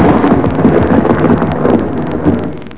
Thunder
THUNDER.wav